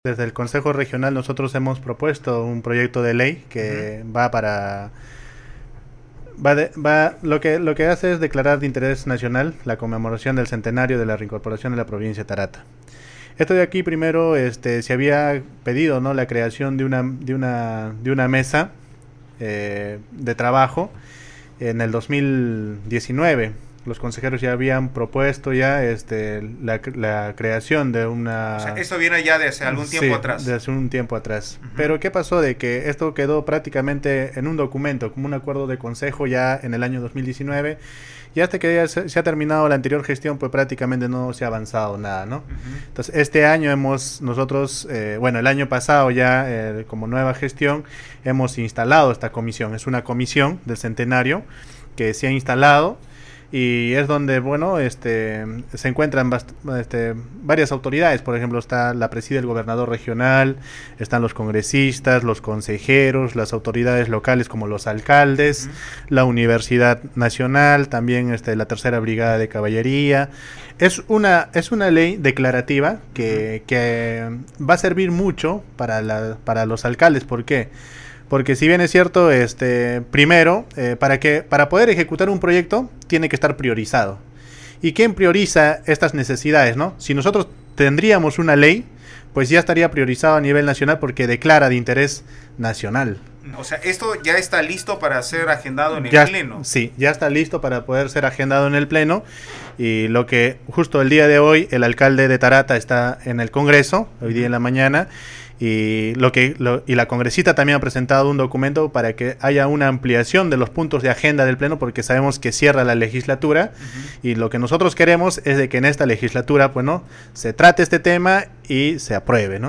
04.-ALDO-TICONA-SE-PRONUNCIA-PARA-APROBACION-DE-LEY-TARATA-EN-CONGRESO.mp3